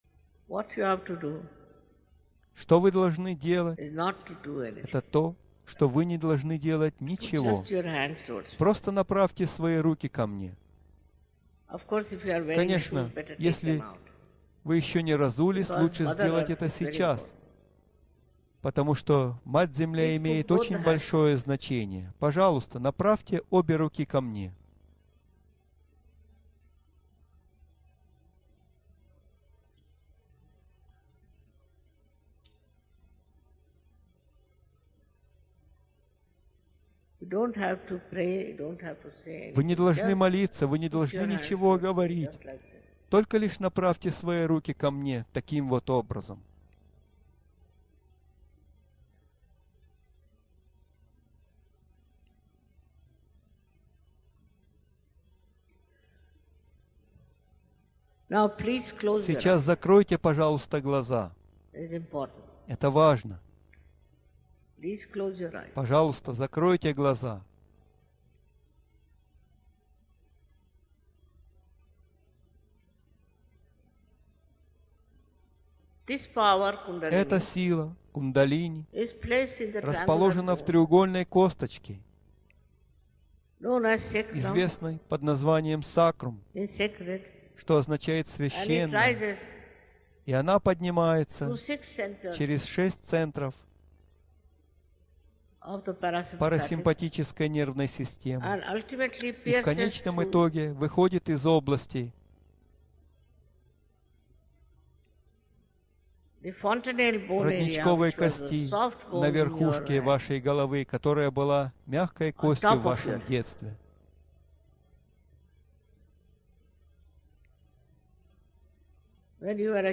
аудіо файл з інструкціями Шрі Матаджі.